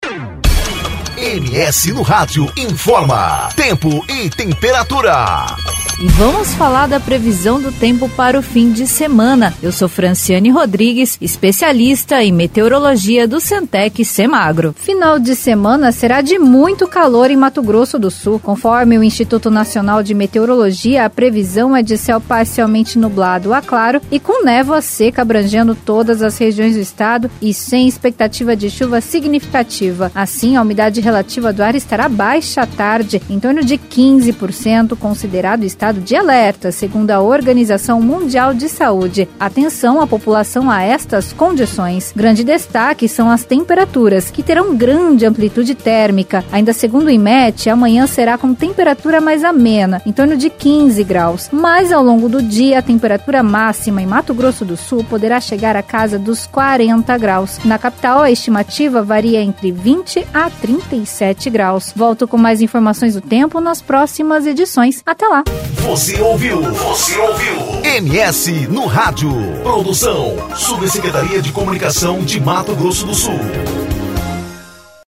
Confira a previsão do tempo do Cemtec/MS para o final de semana